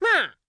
Cat Attack Sound Effect
Download a high-quality cat attack sound effect.
cat-attack.mp3